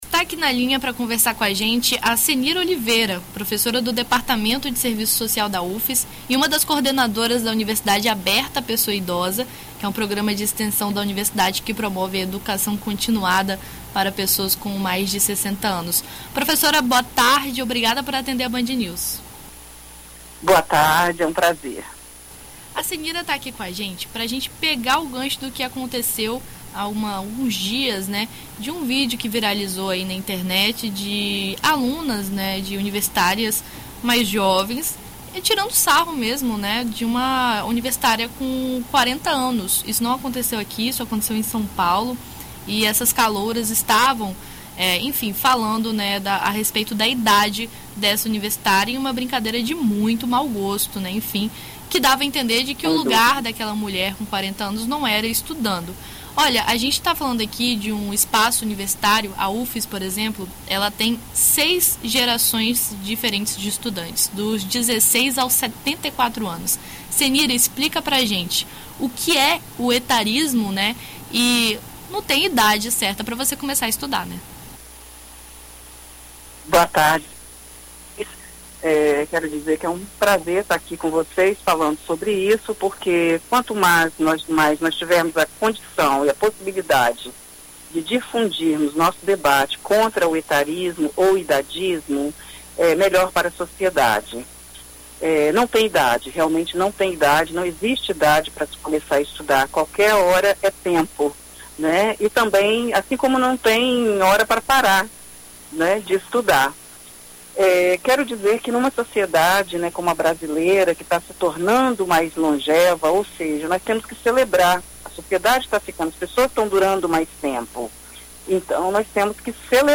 Em entrevista à BandNews FM ES nesta segunda